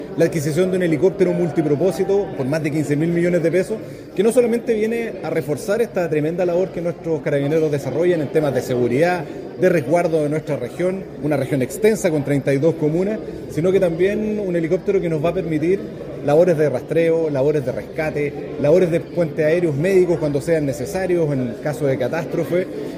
Sobre este importante mensaje que permitirá a Carabineros de La Araucanía modernizar su Sección Aeropolicial con este helicóptero, el gobernador Luciano Rivas señaló que se trata de una gran noticia para la región.